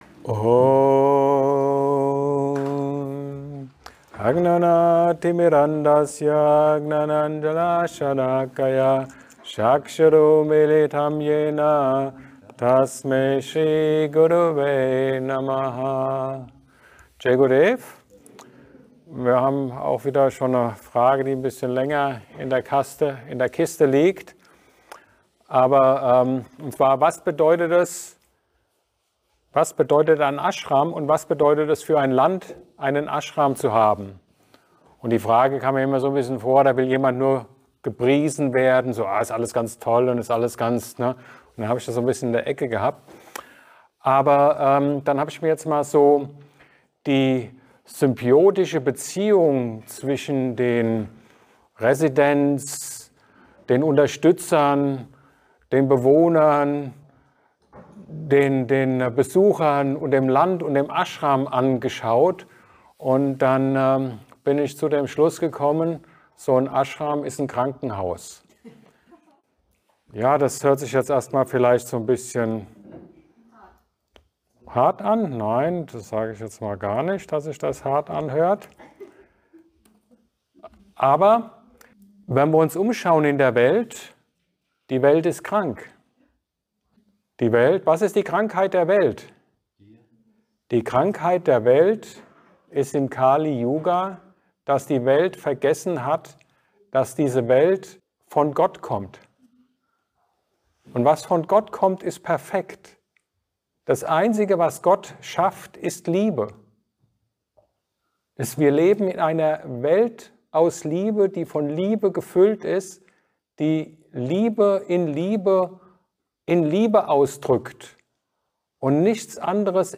Ein Satsang